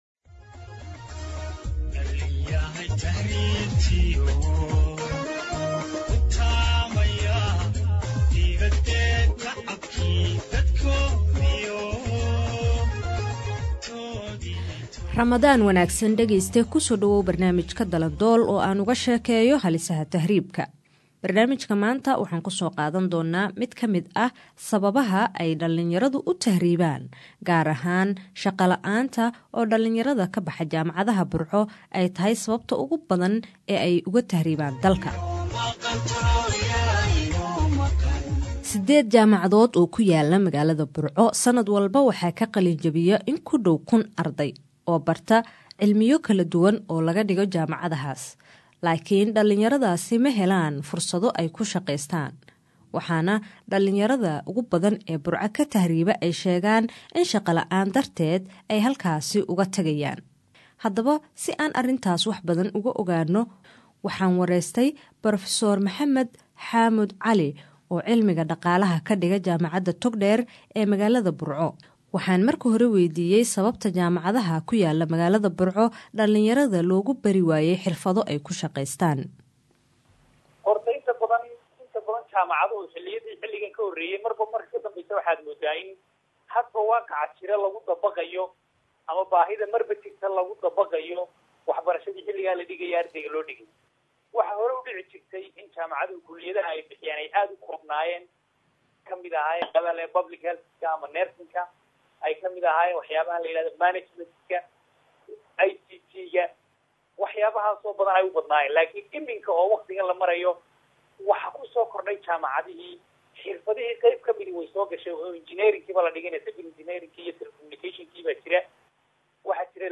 Barnaamijkeena dalandool waxaan uga hadalla halisaha tahriibka, wuxuuna maanta ku saabsan yahay Burco oo ka mid ah meelaha ay dhallinyarada aadka uga tahriibaan shaqo la’aanta darteed, ayaan ka wareystay nin jaamacad macallin ka ah.